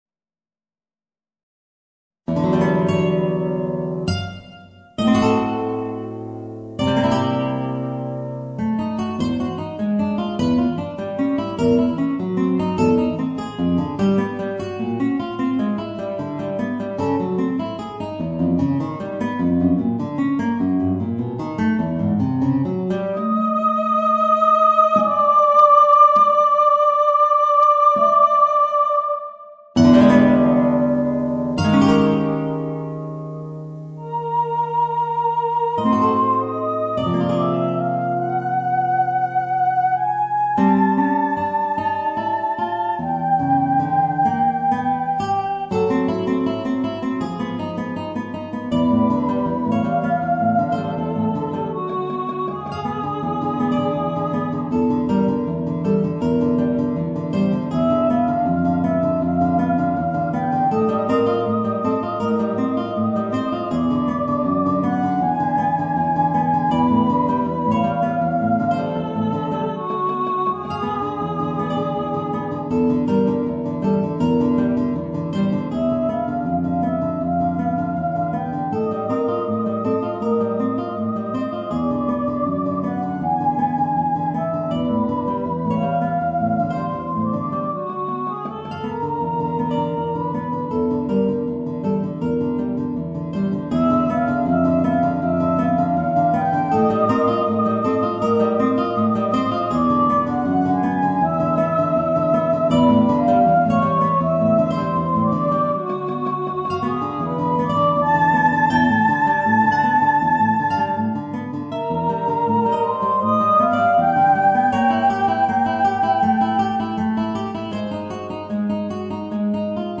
Guitar & Soprano (Voice)